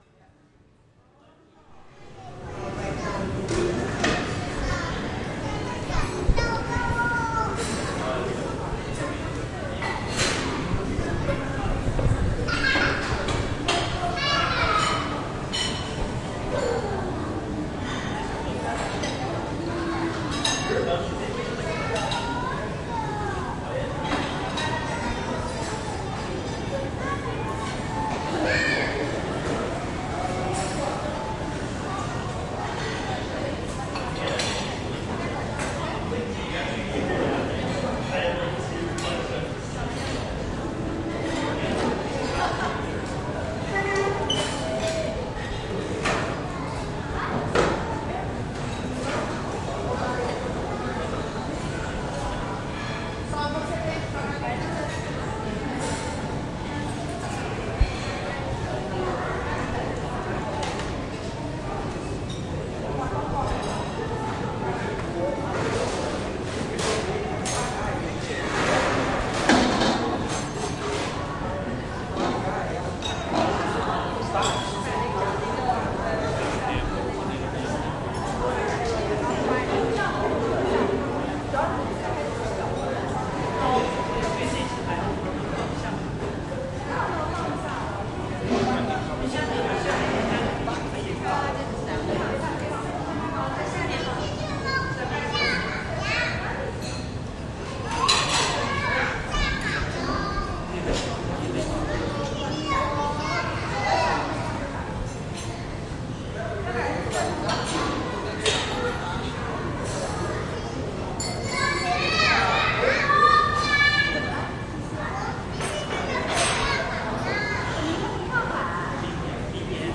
拥挤的地方 " 拥挤的地方ikea
描述：宜家帕洛阿尔托自助餐厅的噪音。用RØDEiXY记录
Tag: 社会 食堂 环境